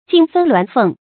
鏡分鸞鳳 注音： ㄐㄧㄥˋ ㄈㄣ ㄌㄨㄢˊ ㄈㄥˋ 讀音讀法： 意思解釋： 比喻夫妻分離。